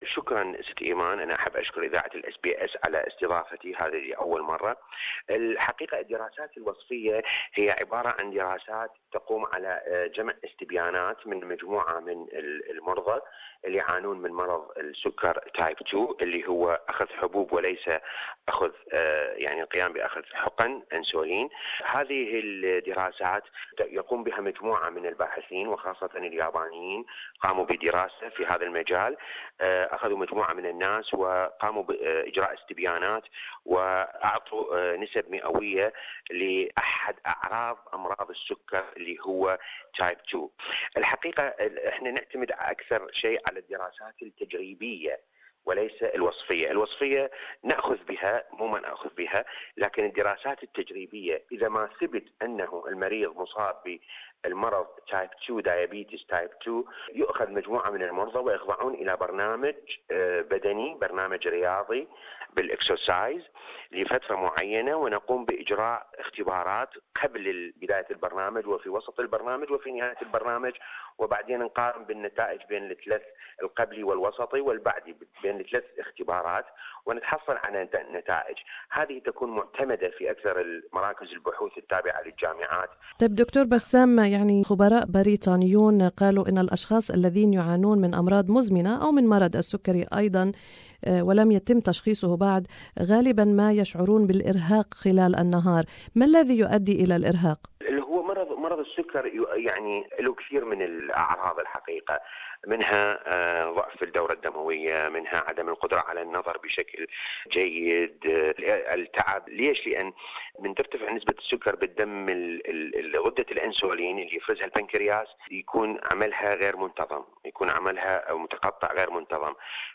A study presented at a scientific congress Thursday reported a link between long naps and a higher risk of diabetes, though it couldn't say if daytime sleeping was a symptom or a cause. Interview